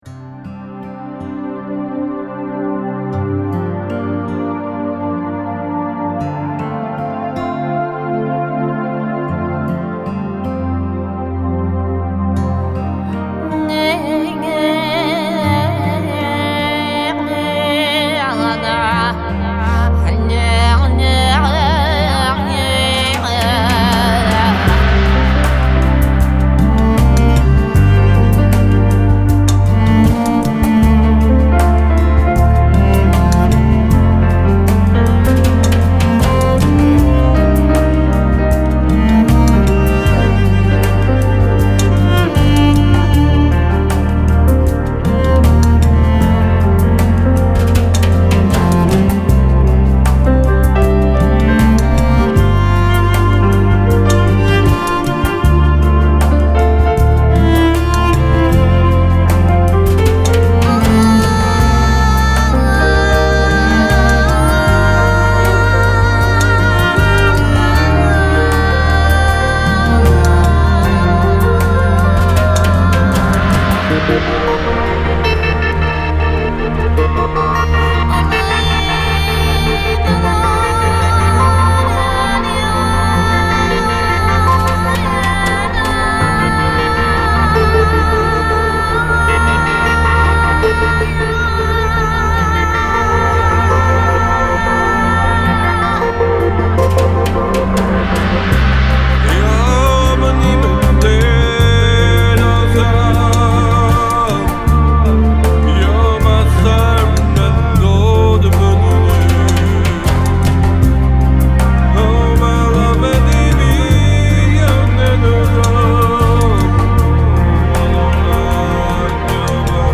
Медитативная музыка Релакс Нью эйдж New Age Музыка релакс